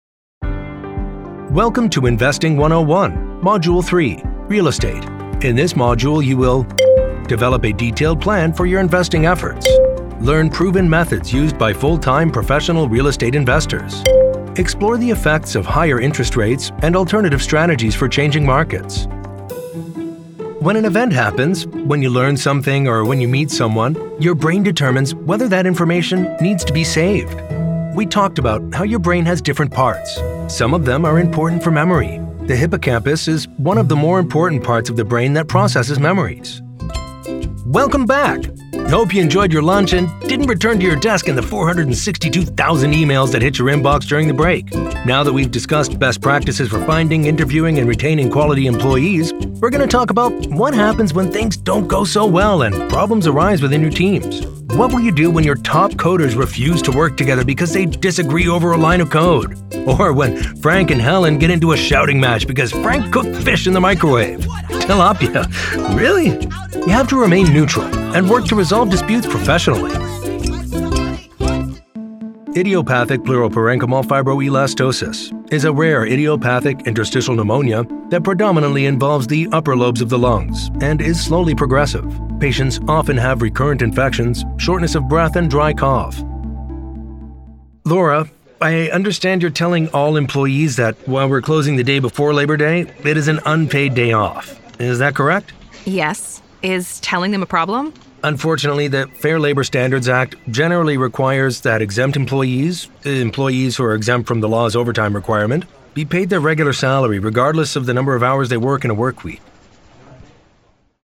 Inglés (Americano)
Profundo, Natural, Llamativo, Versátil, Cálida
E-learning